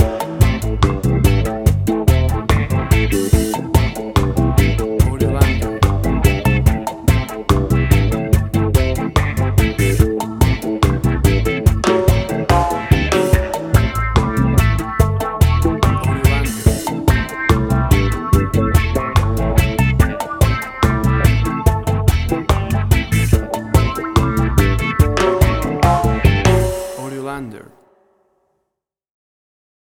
WAV Sample Rate: 16-Bit stereo, 44.1 kHz
Tempo (BPM): 72